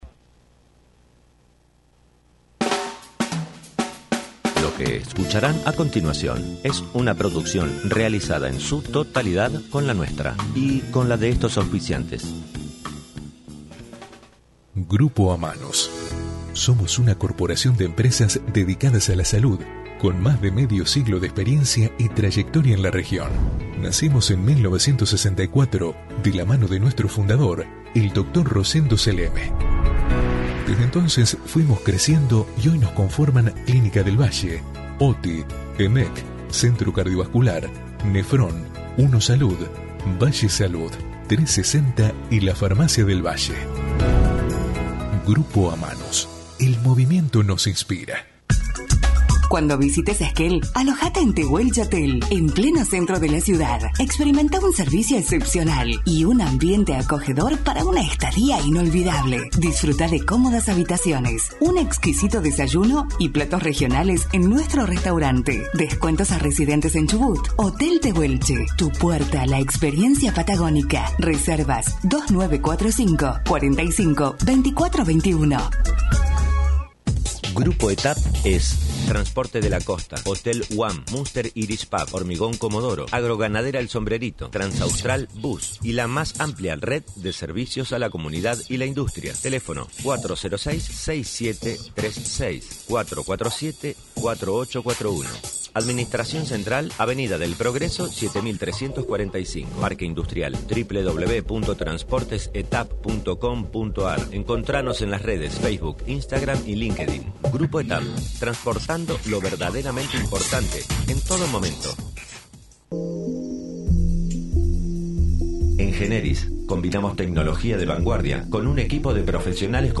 Othar Macharashvilli, intendente de Comodoro Rivadavia, visitó los estudios de LaCienPuntoUno para hablar en “Con La Nuestra” sobre la actualidad de la ciudad y los proyectos. Además, se refirió a lo que fue la 10º Expo Industrial y Comercial, Innovación Tecnológica y la conversación por el Presupuesto Nacional 2025.